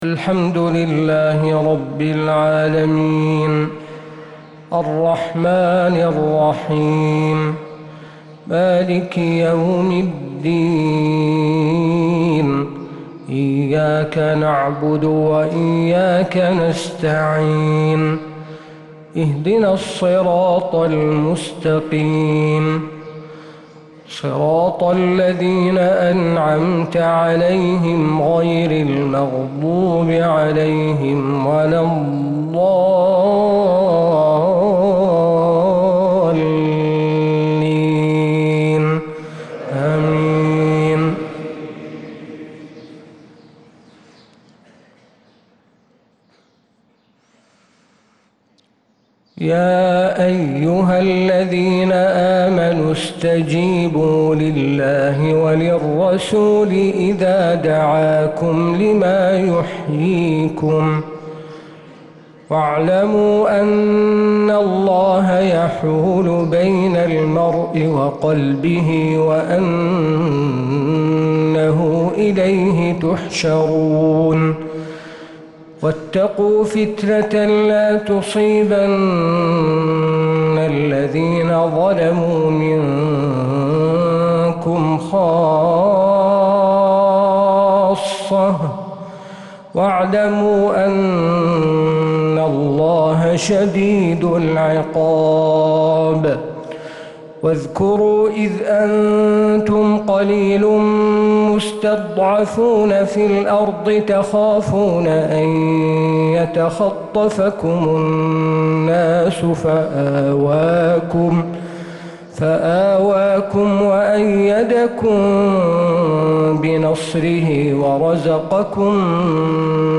فجر السبت 9-8-1446هـ من سورة الأنفال 24-44 | Fajr prayer from Surat al-Anfal 8-2-2025 > 1446 🕌 > الفروض - تلاوات الحرمين